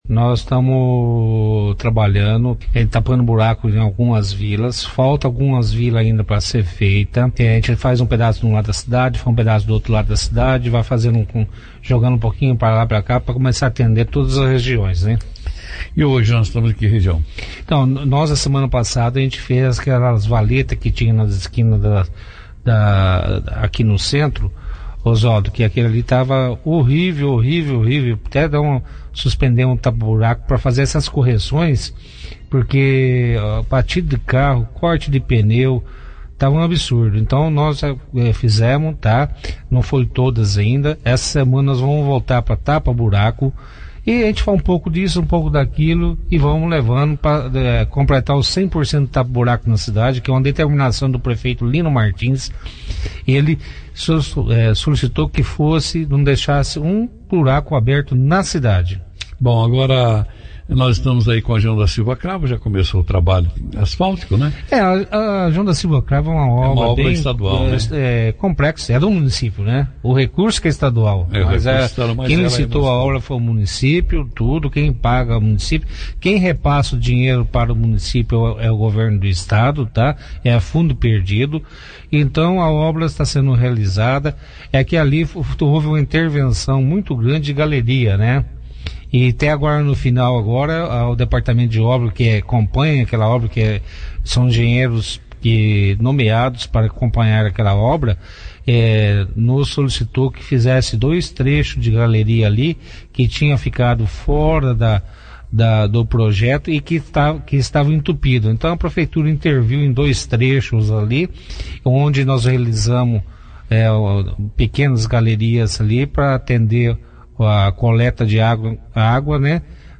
O secretário de administração Antônio Carlos Zanardo, (foto), participou da 2ª edição do jornal Operação Cidade desta segunda-feira, 12/08/19, falando sobre a operação tapa-buraco que vem sendo desenvolvida pela prefeitura em algumas vilas após a instalação e o funcionamento da usina de asfalto.